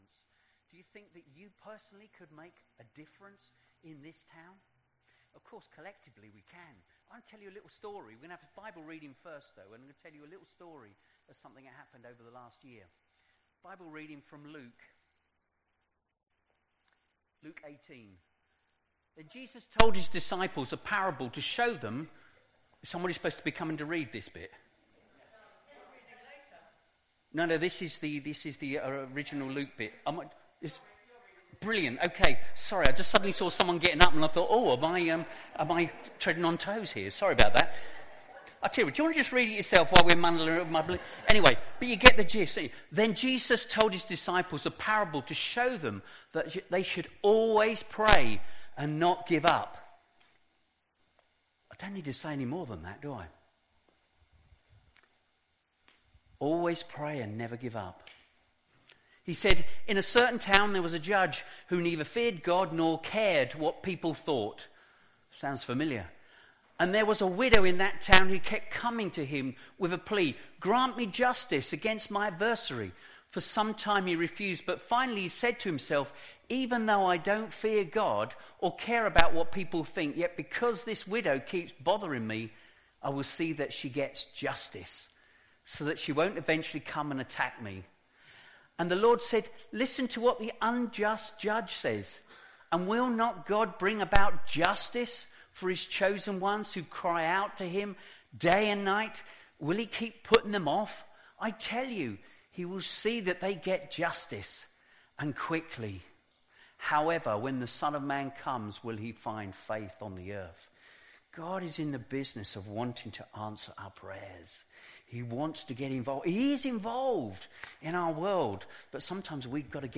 An audio version of the All Age talk and sermon is also available.
04-27-YP-talk-sermon.mp3